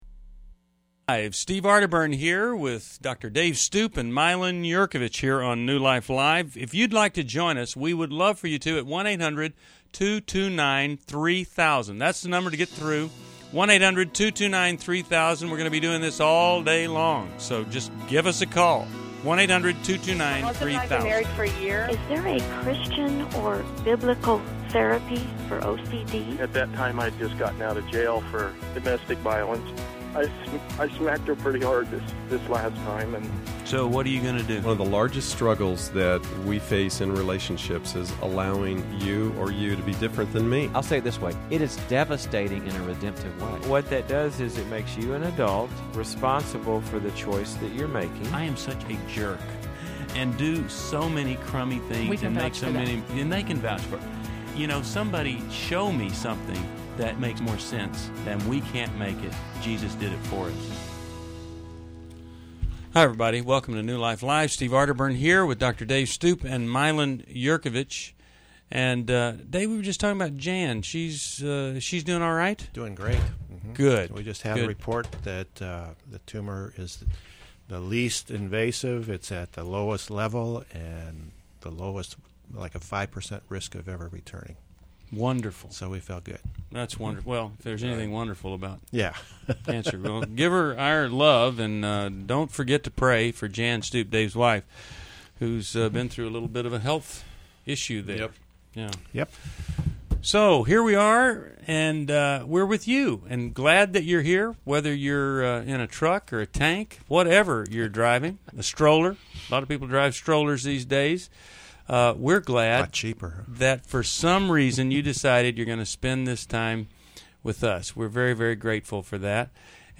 Explore grief, blended family dynamics, and divorce strategies in New Life Live: June 29, 2011, as hosts guide callers through personal challenges.